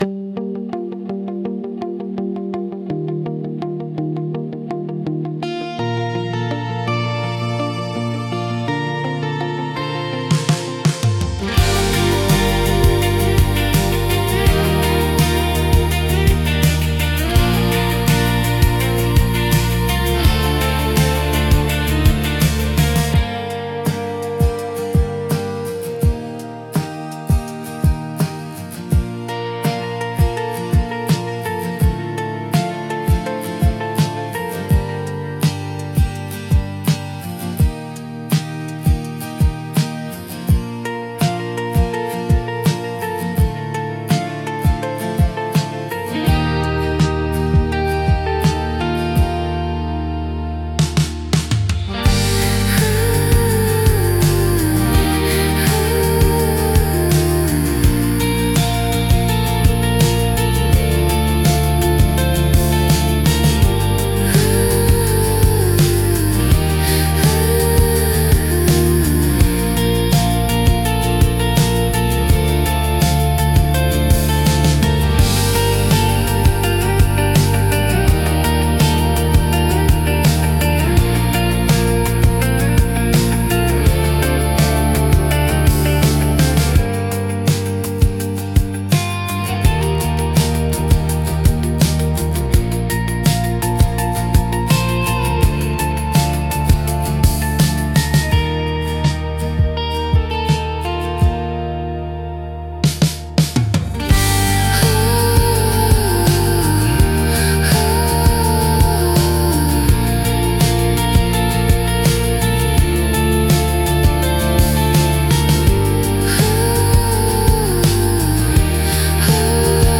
静かで美しい音の重なりが心地よく、感性を刺激しながらも邪魔にならない背景音楽として活用されます。